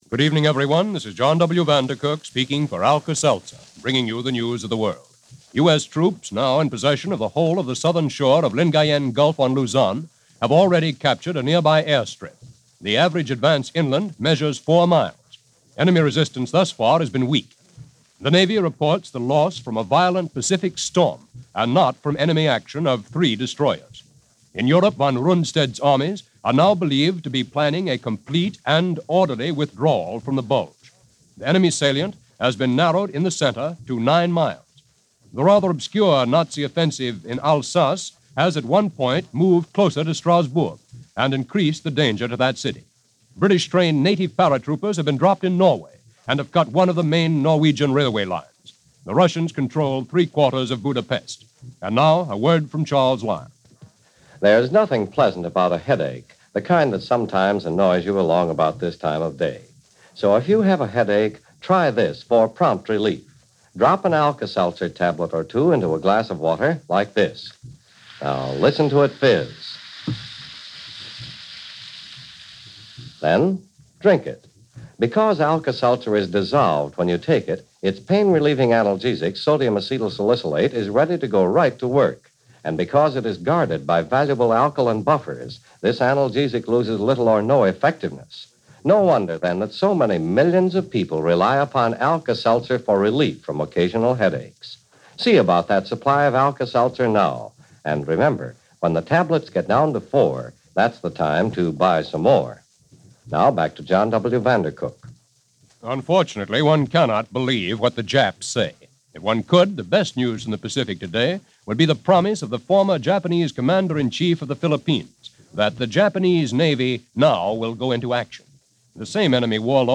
recorded at The London Astoria and The Brixton Academy
In Concert from London